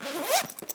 action_open_backpack_3.ogg